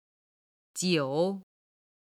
ただ軽音部分の音源がないので、元々の単語の四声にしています。
音源には本来の四声の数字を表記(軽声は5と表記)、音と目で音源の四声が分かるようにしています。